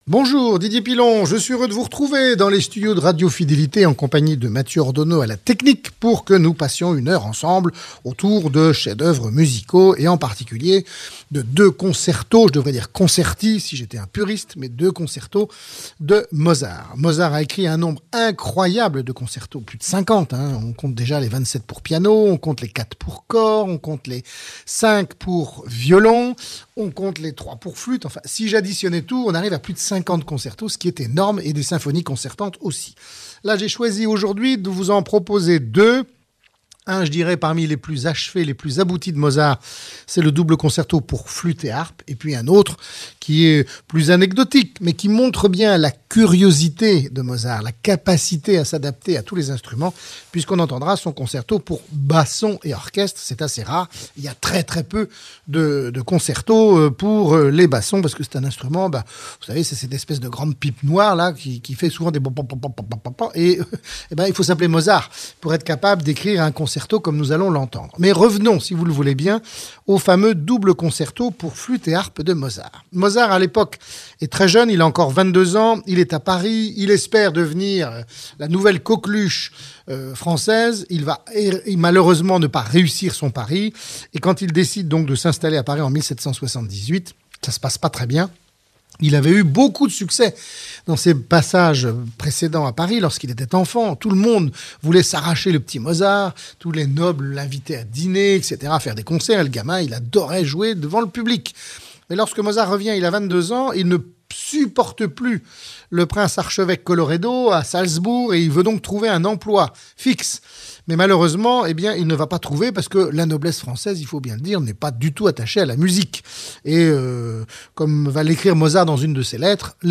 DP Mozart - Concerto pour flûte et harpe, concerto pour basson et orchestre